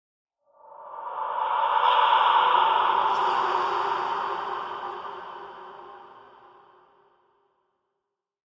Minecraft Version Minecraft Version latest Latest Release | Latest Snapshot latest / assets / minecraft / sounds / ambient / nether / soulsand_valley / mood4.ogg Compare With Compare With Latest Release | Latest Snapshot